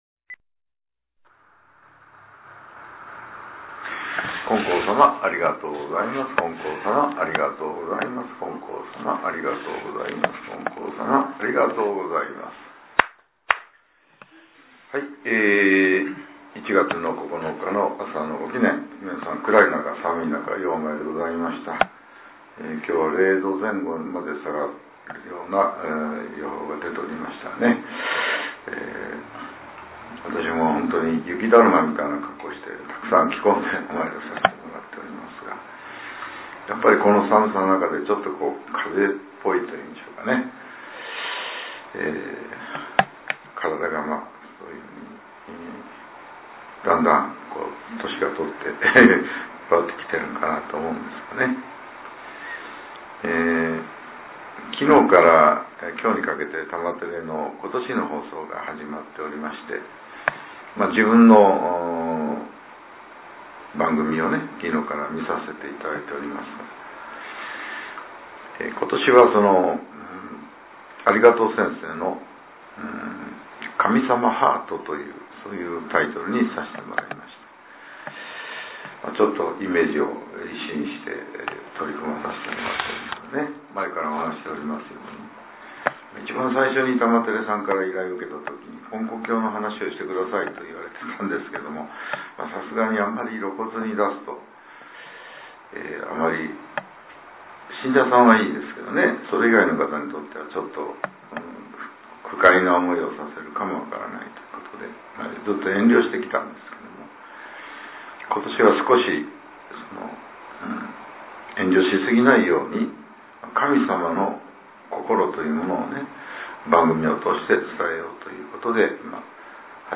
令和８年１月９日（朝）のお話が、音声ブログとして更新させれています。